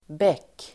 Uttal: [bek:]